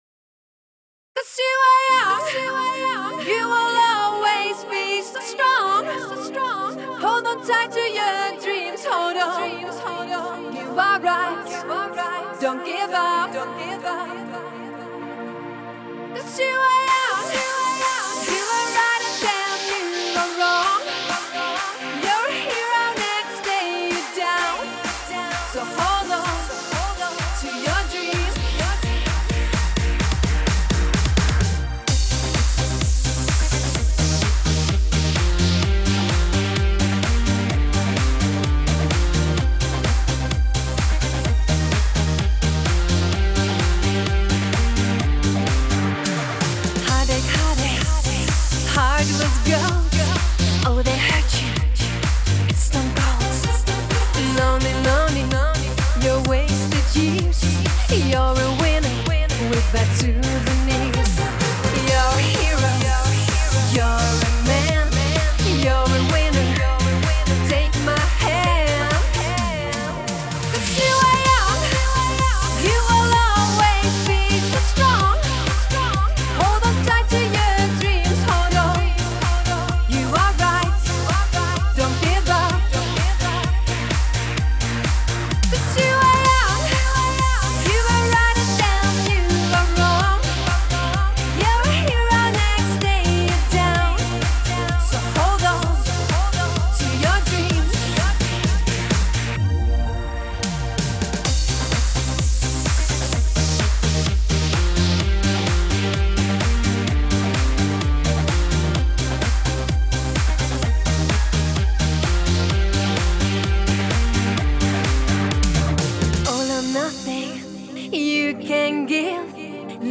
Жанр: Disco